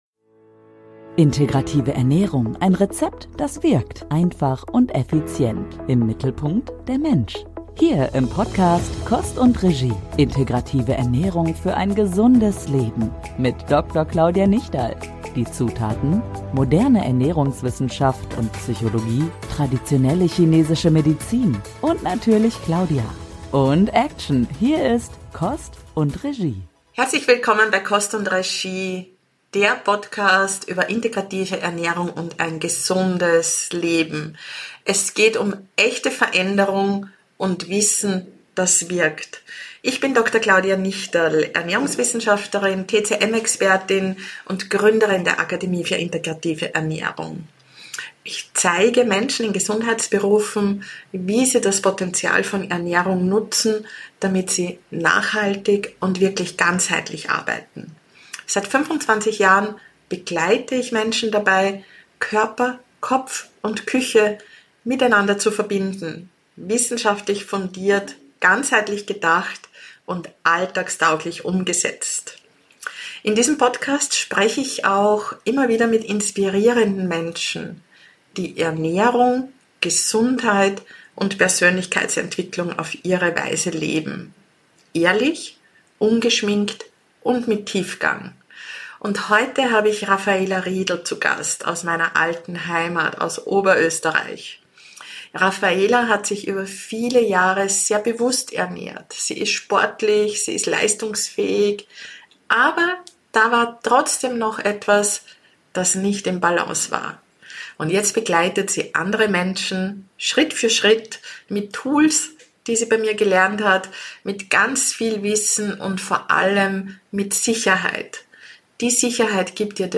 Ein inspirierendes Gespräch über Mut statt Perfektion, über die Kraft des Tuns und darüber, wie man das scheinbar Einfache wirklich lebt.